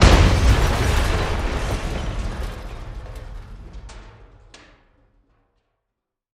Artillery Explosion (Close) (Mixed)